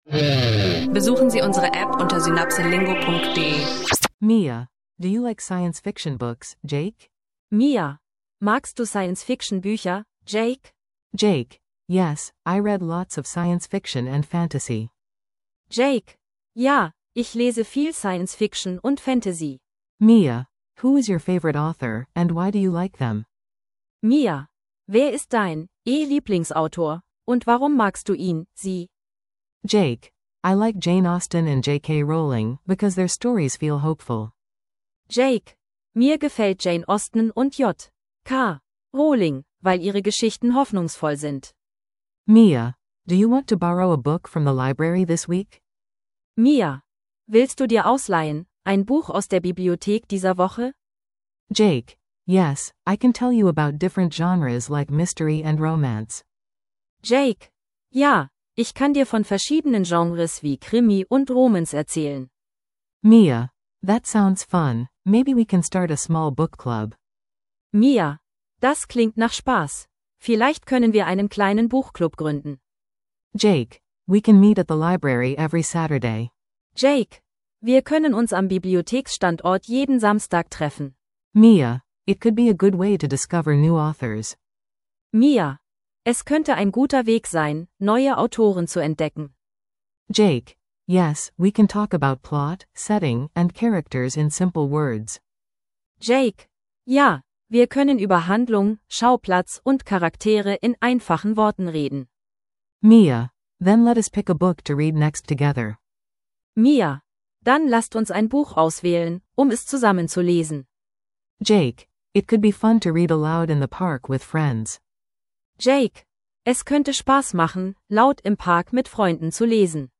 Kurze Dialoge zu Büchern, Autoren und Genres – leicht verständlich Englisch lernen mit Alltagssprache.